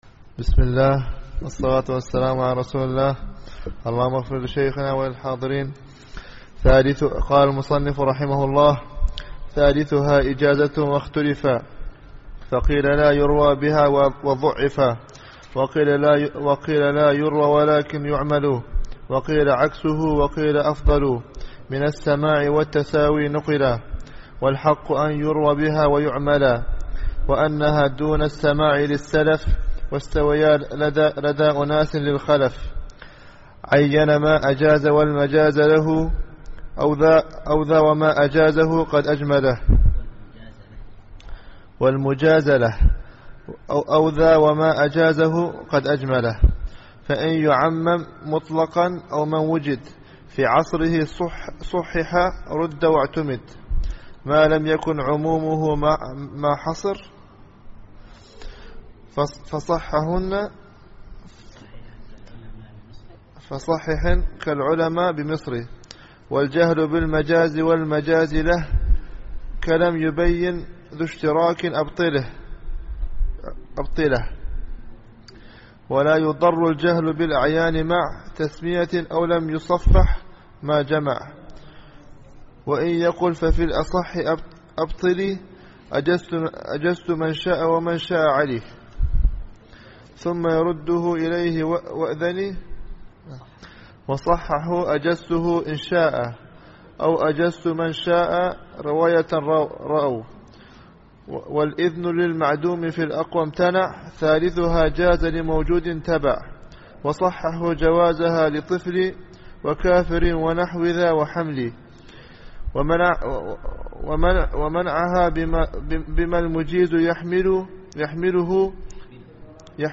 الدرس التاسع عشر